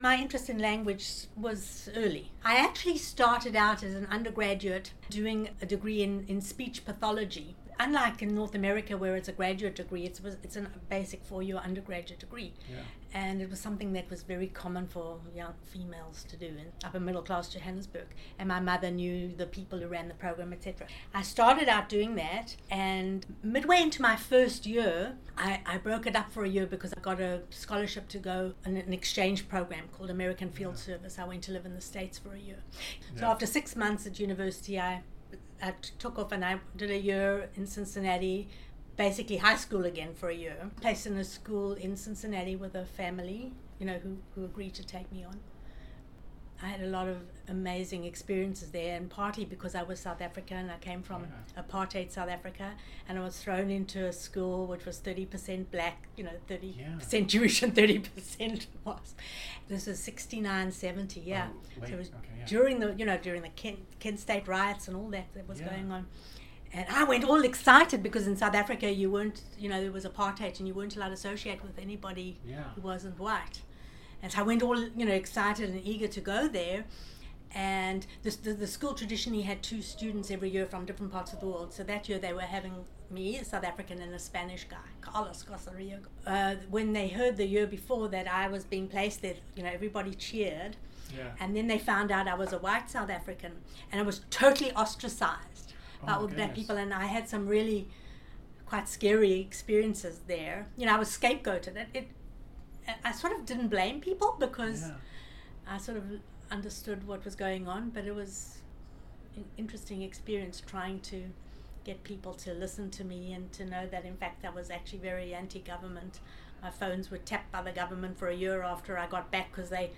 This influence manifest itself in several ways, and the following excerpt from our conversation addresses that. She describes a travel abroad experience as a young woman to the United States.